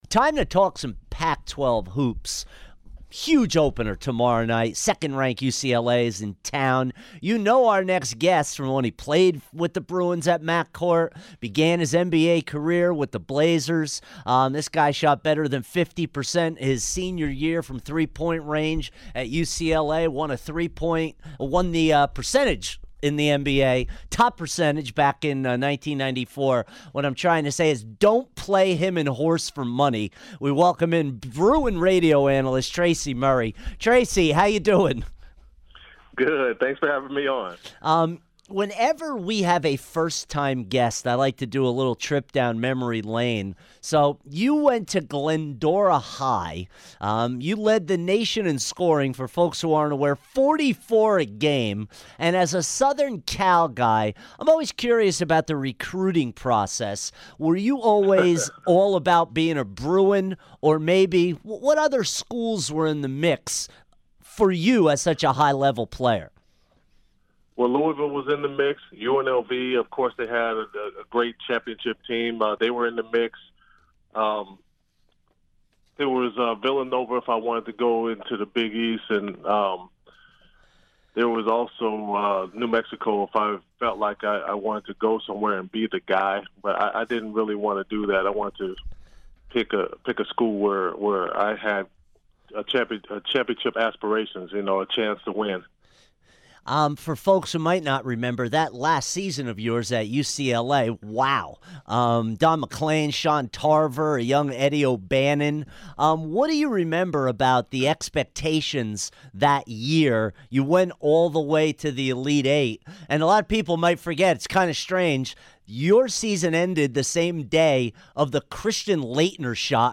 Tracy Murray Interview 12-27-16